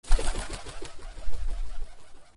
bidrflyaway.e0b4af7d5c3c3ca6ea98.mp3